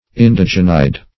Search Result for " indogenide" : The Collaborative International Dictionary of English v.0.48: Indogenide \In"do*gen*ide\, n. (Chem.) Any one of the derivatives of indogen, which contain that group as a nucleus.
indogenide.mp3